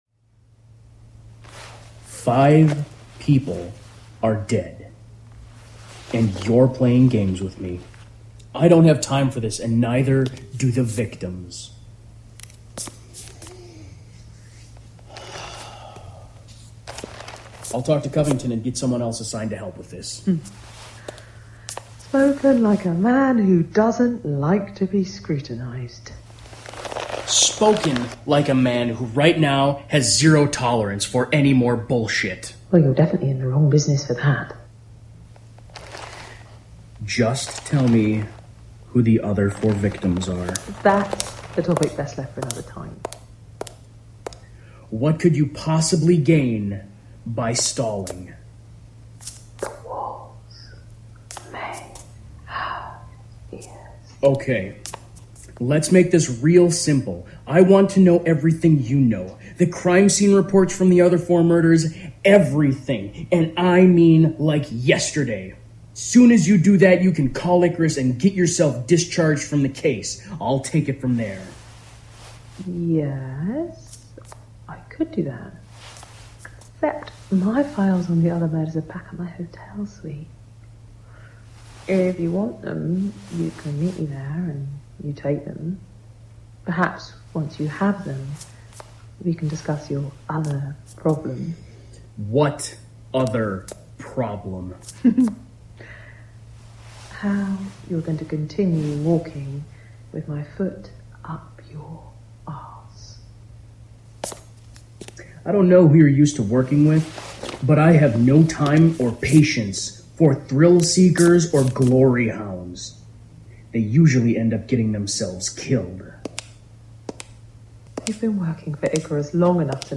Just to give you a heads up that now all the effects have been added yet and neither has the soundtrack but the clips should give you a good idea what to expect.